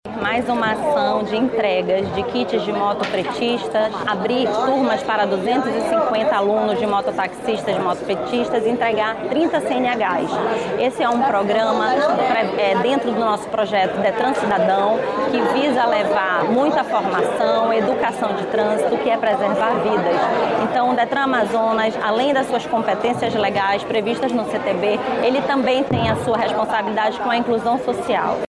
A diretora-presidente interina do Detran-AM, Thanny Gusmão, destacou que a ação integra um conjunto de medidas voltadas à inclusão e à educação no trânsito.